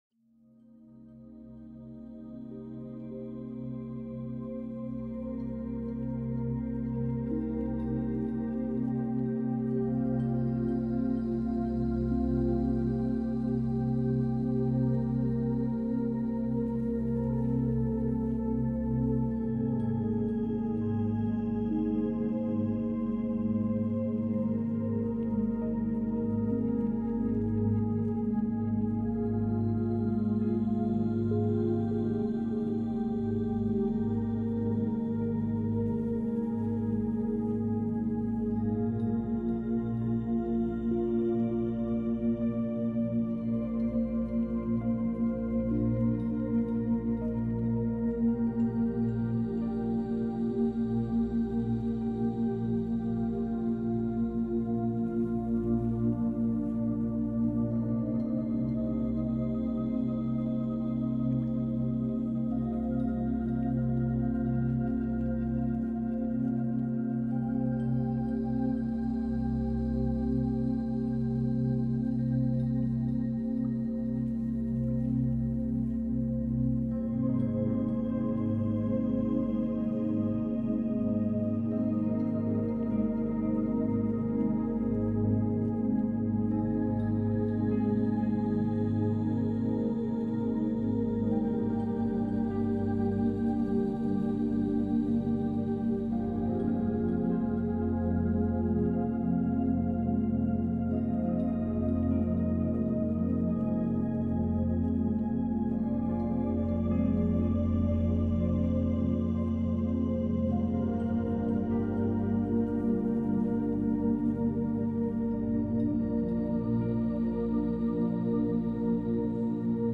Nature nocturne douce · méthode essentielle pour apprendre le soir